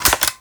BUTTON_STOP_02.wav